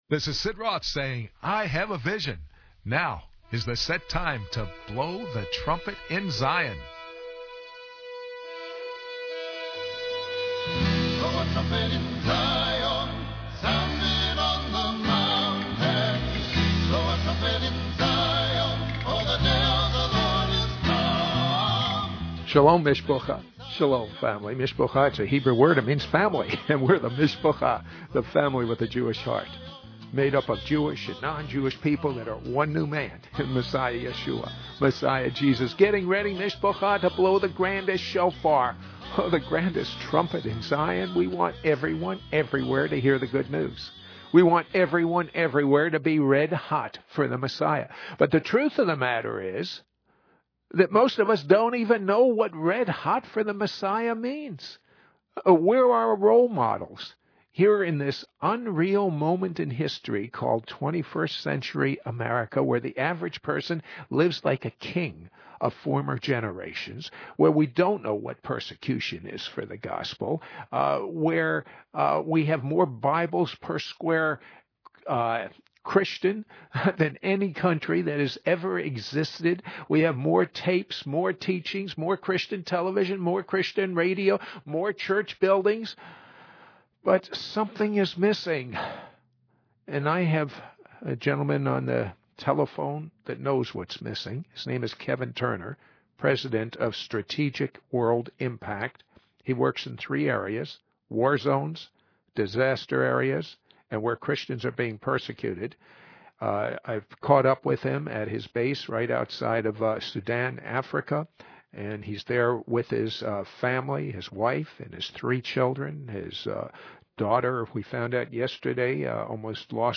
Radio Interview From Sudan Eritrea Border - Part 1